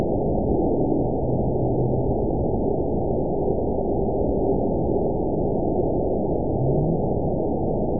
event 921098 date 04/28/24 time 19:06:57 GMT (1 year, 1 month ago) score 8.99 location TSS-AB06 detected by nrw target species NRW annotations +NRW Spectrogram: Frequency (kHz) vs. Time (s) audio not available .wav